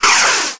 jet02.mp3